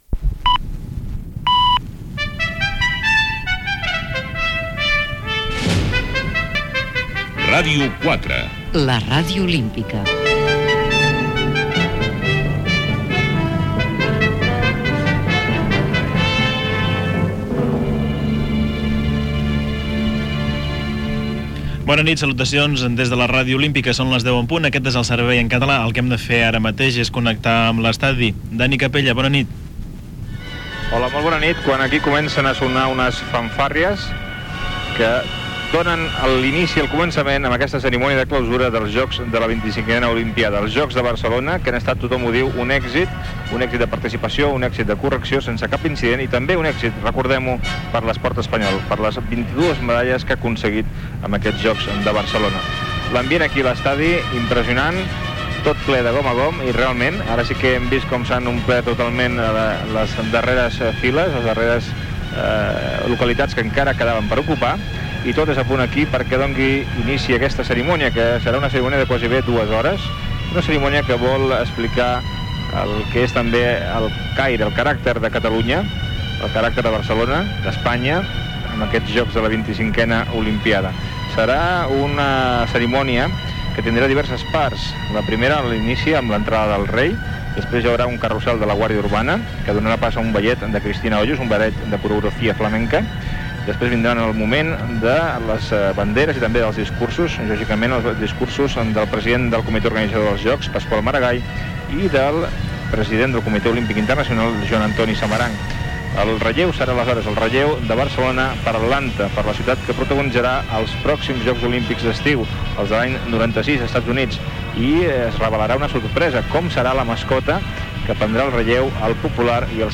informatiu en anglès amb connexió amb l'estadi Lluís Companys Gènere radiofònic Informatiu